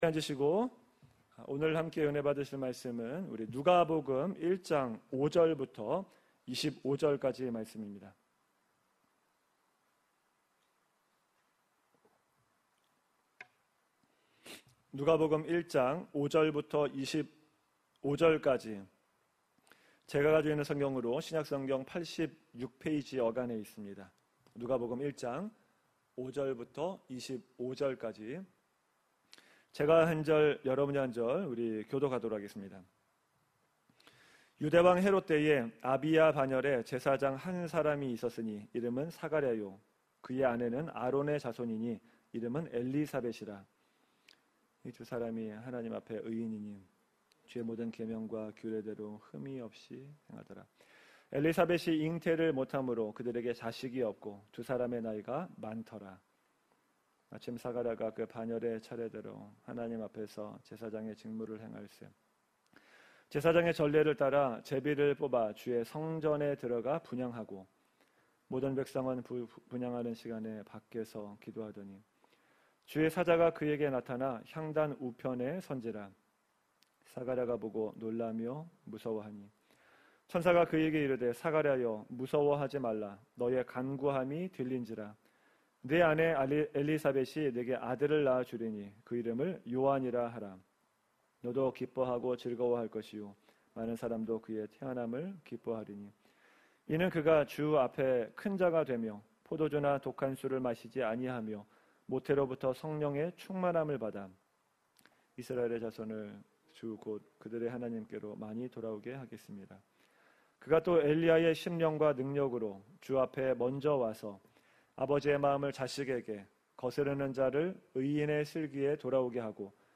Like this: Like Loading... 2020 주일설교